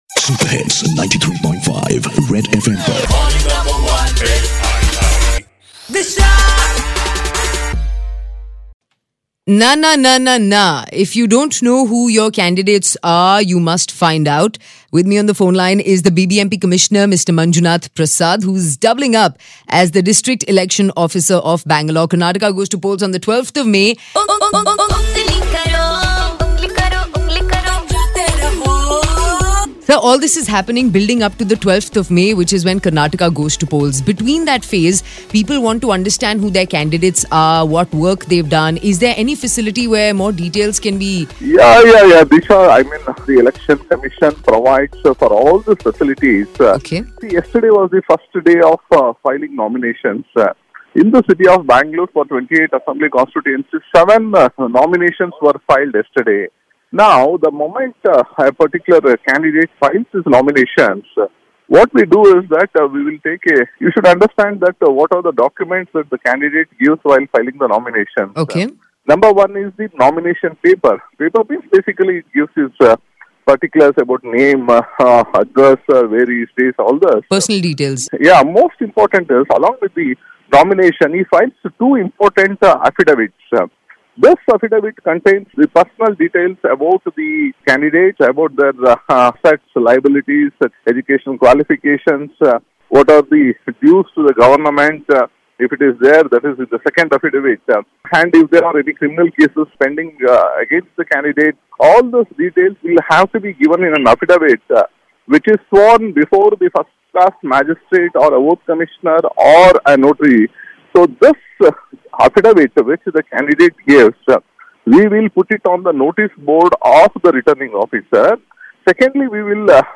BBMP Commissioner,Manjunath Prasad explaining about Know Your Candidate